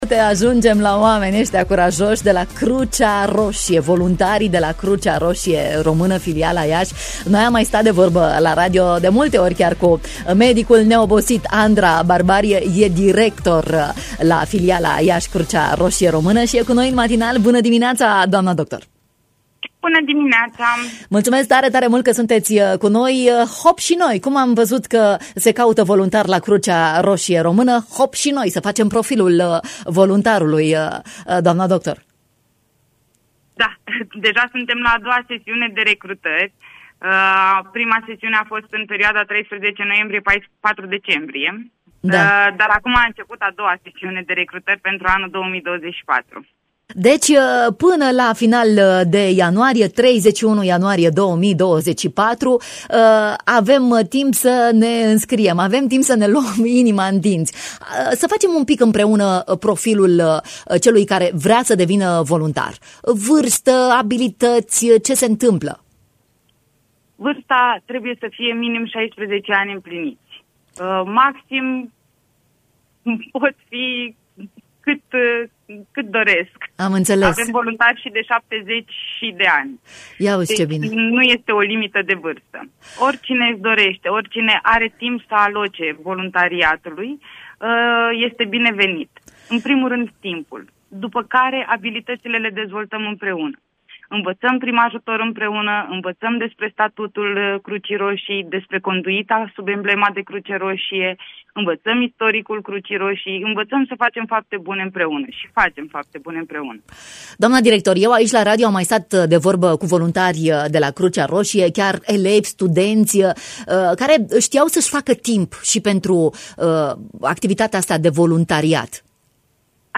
Profilul voluntarului Crucea Roșie, în matinalul Radio Iași